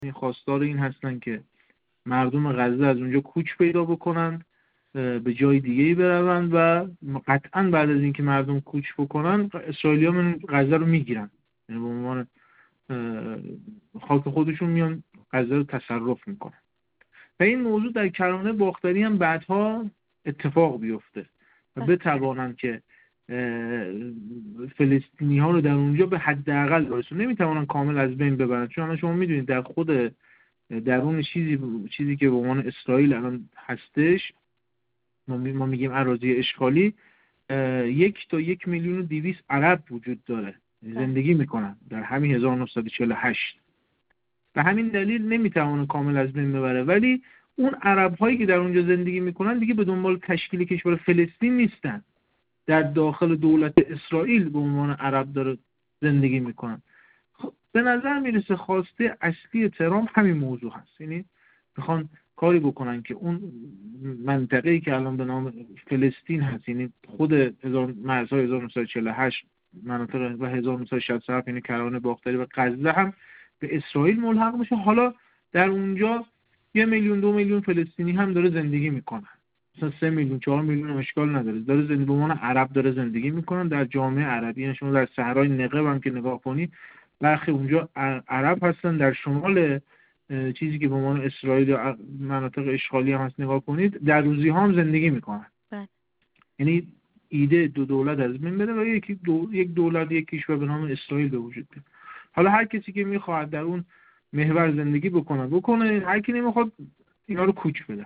کفت‌وگو